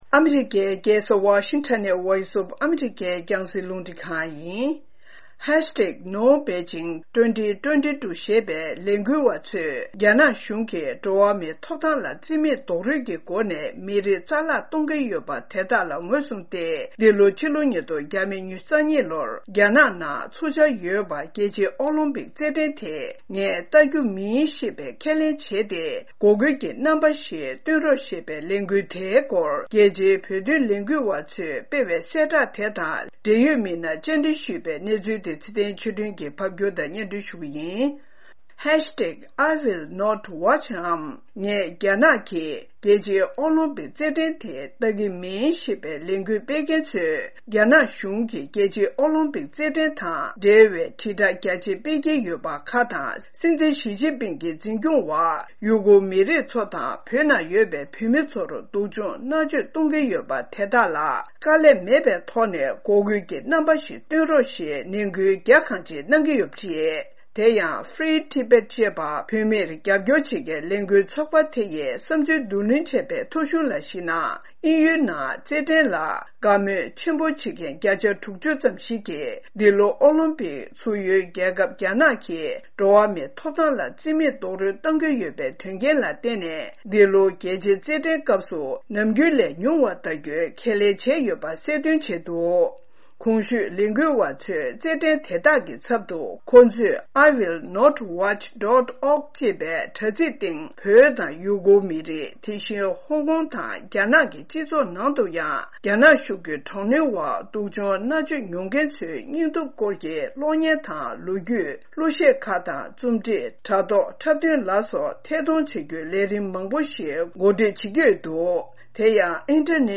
༄༅།། ངས་༢༠༢༢་ལོའི་པེ་ཅིང་གི་རྩེད་འགྲན་ལ་བལྟ་རྒྱུ་མིན་ཞེས་པའི་ལས་འགུལ་བ་ཚོས་རྒྱ་ནག་གཞུང་གིས་འགྲོ་བ་མིའི་ཐོབ་ཐང་ལ་བརྩི་མེད་རྡོག་རོལ་དང་མི་རིགས་རྩ་མེད་གཏོང་གི་ཡོད་པ་དེ་དག་ངོས་བཟུང་སྟེ་༢༠༢༢་ལོར་རྒྱ་ནག་གི་རྒྱལ་ས་པེ་ཅིང་ལ་འཚོགས་འཆར་ཡོད་པའི་རྒྱལ་སྤྱིའི་ཨོ་ལིམ་ཕིཀ་རྩེད་ འགྲན་ལ་བལྟ་རྒྱུ་མིན་ཞེས་པའི་ཁས་ལེན་བྱས་ཏེ་འགོག་རྒོལ་གྱི་རྣམ་པ་ཞིག་སྟོན་དགོས་པའི་འབོད་སྐུལ་ཞུས་འདུག་པའི་སྐོར། རྒྱལ་སྤྱིའི་བོད་དོན་ལས་འགུལ་བ་ཚོས་སྤེལ་བའི་གསལ་བསྒྲགས་དང་འབྲེལ་ཡོད་མི་སྣར་བཅར་འདྲི་ཞུས་པའི་གནས་ཚུལ་